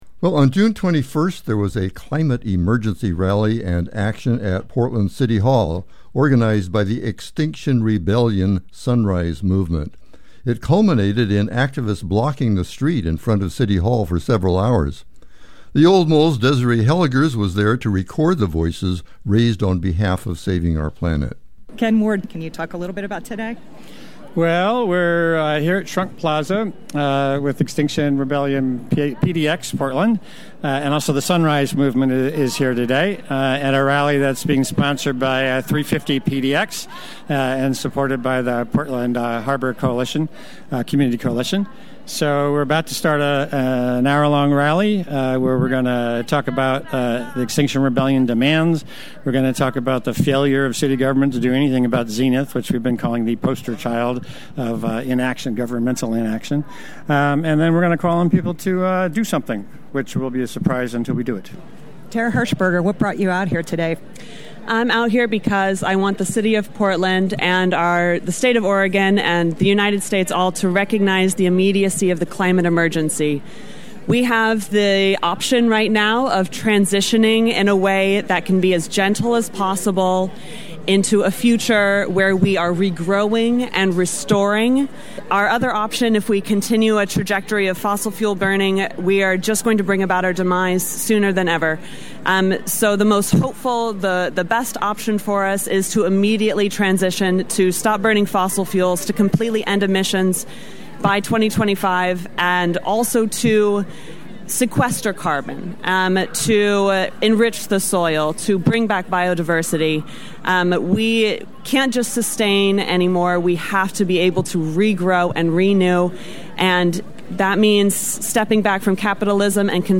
Voices from the June 21 Extinction Rebellion/Sunrise Movement rally in downtown Portland that culminated with activists blocking the street in front of Portland City Hall for several hours. Among the activists' demands was a call for Mayor Wheeler to declare a climate emergency, halt Xenith Energy's transport of Tar Sands oil through Portland and the proposed freeway expansion.
extinction_rebellion_rally.mp3